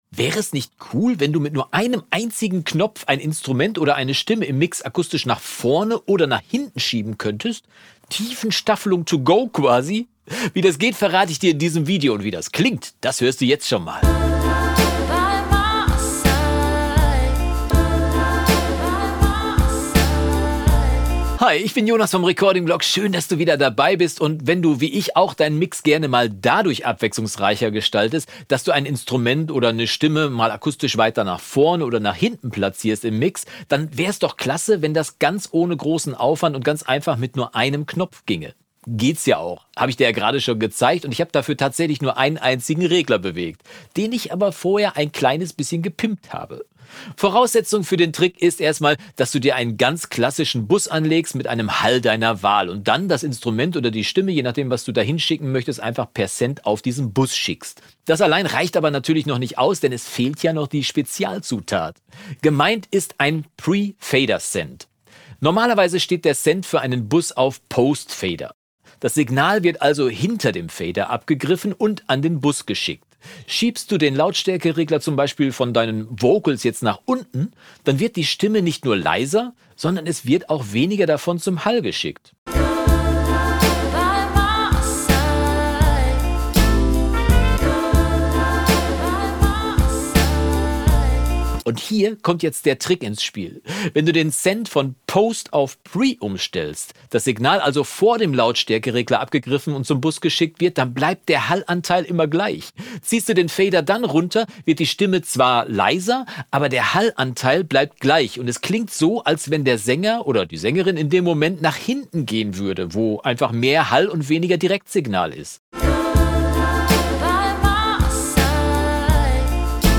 Easy: Tiefenstaffelung mit nur einem Knopf | Abmischen Tutorial | Recording-Blog MP143